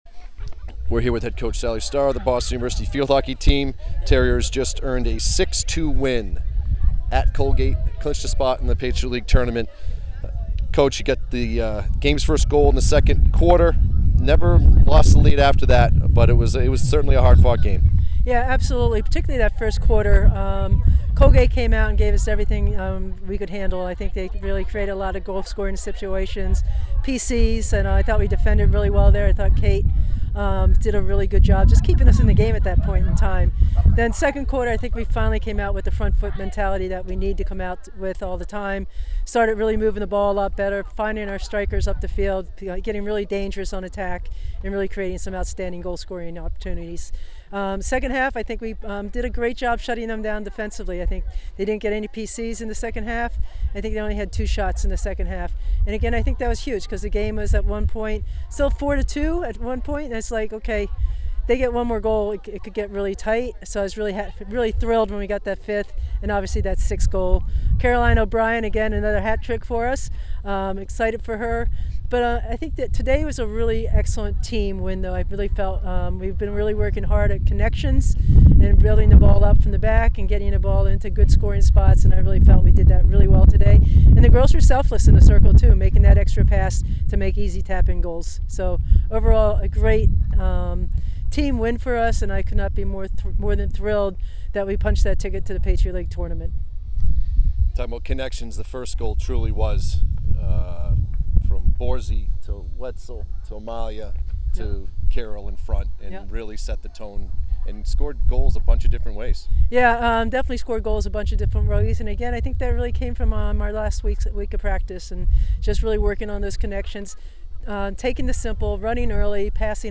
Field Hockey / Colgate Postgame Interview